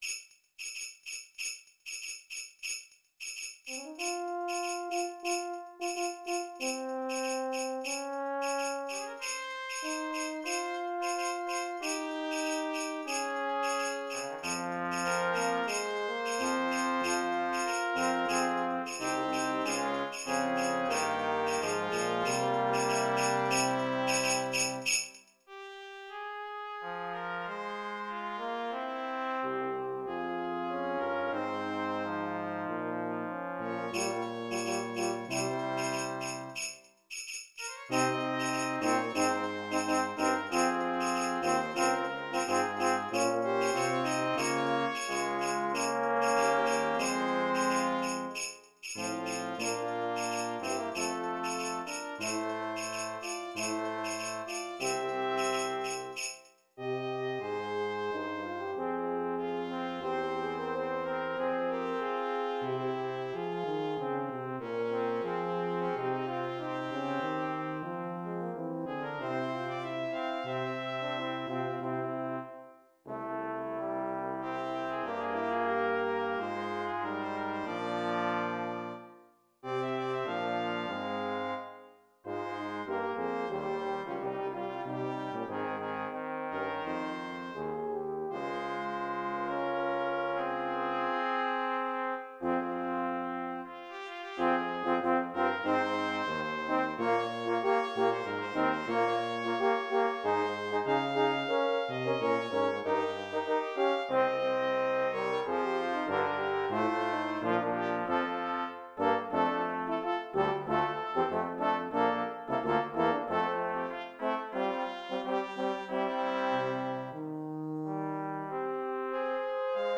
Brass Quintet
Voicing/Instrumentation: Brass Quintet
Instrumental Music or New Age